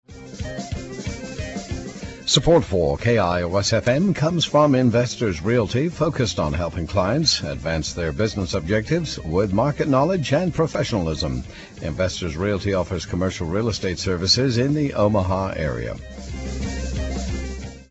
Investors Realty sponsors Omaha Public Radio January 22, 2015 News Tags: Investors Realty radio ad , Omaha Public Radio , support 2015 Investors Realty is proud to be a 2015 supporter of Omaha Public Radio!